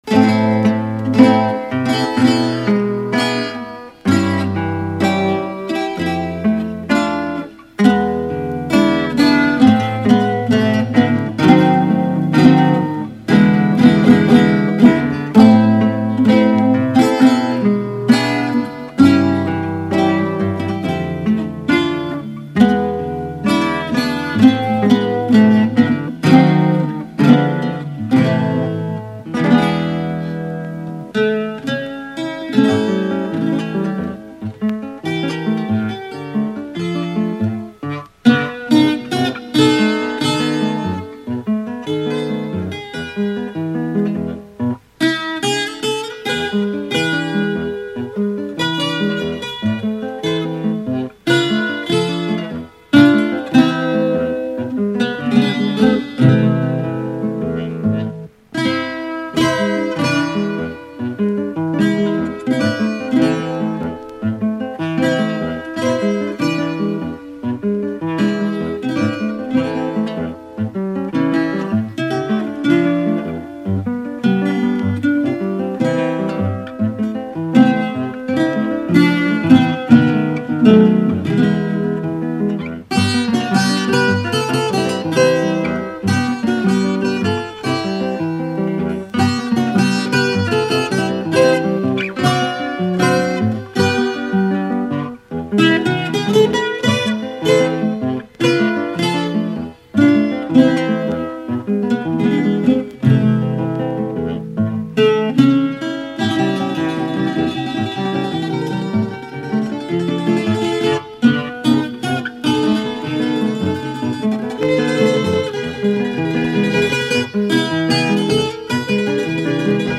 Cuatro y Guitarra